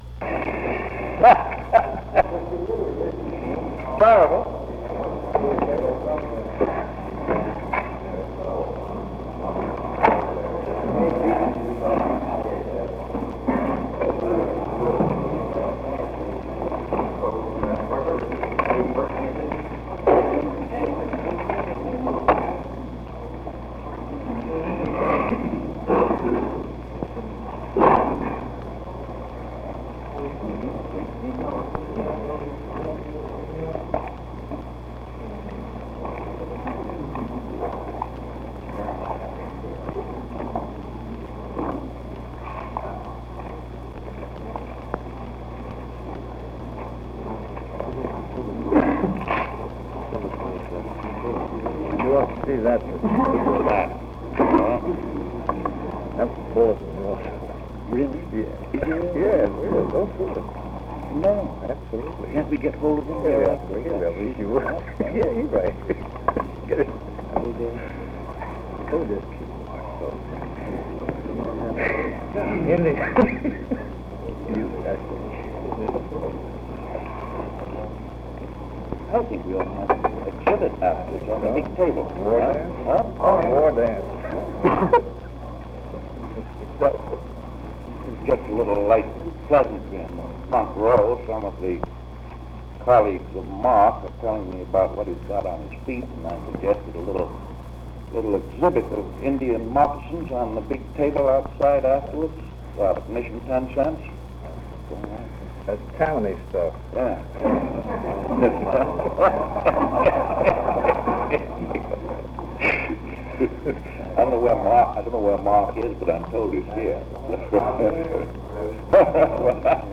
Press Conference 683
Secret White House Tapes | Franklin D. Roosevelt Presidency